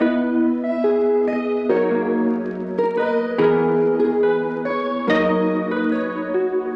描述：这是我用黑胶模拟器处理过的吉他riffs的集合。
标签： 电吉他 吉他 处理吉他 乙烯基
声道立体声